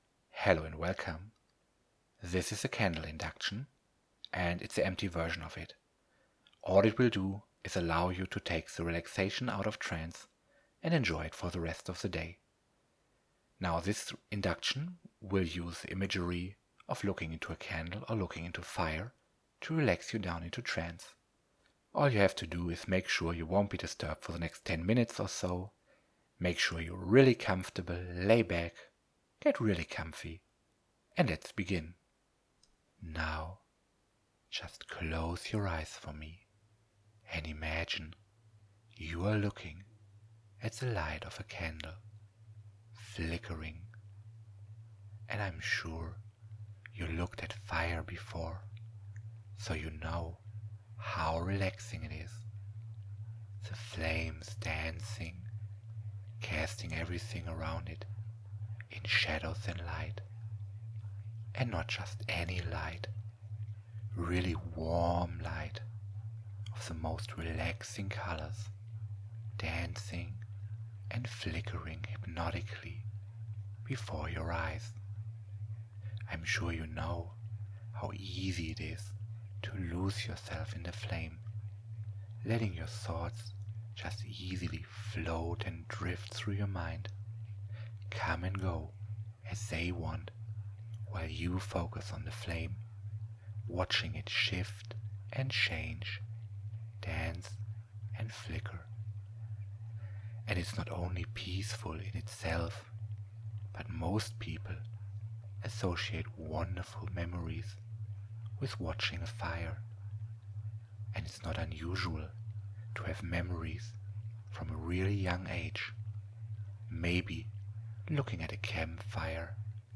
Candlelight Induction
This is the empty induction, but you can take the relaxation out of trance with you.